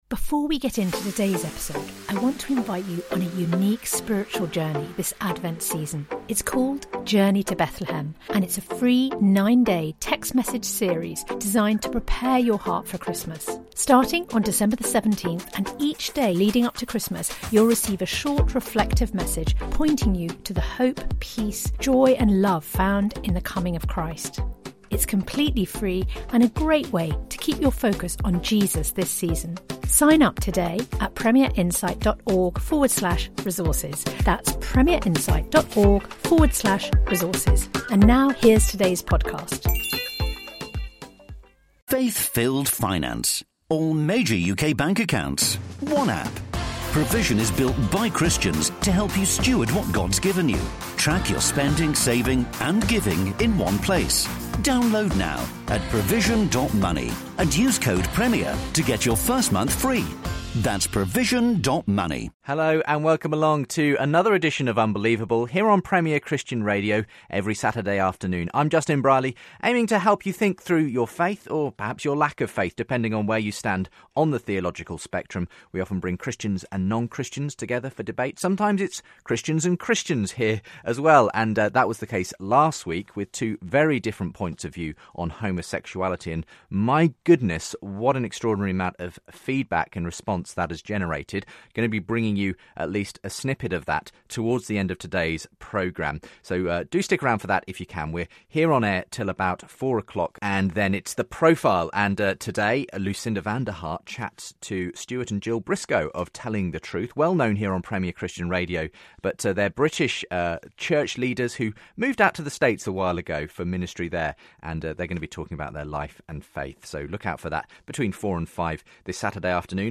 Christianity, Religion & Spirituality 4.6 • 2.3K Ratings 🗓 1 May 2015 ⏱ 83 minutes 🔗 Recording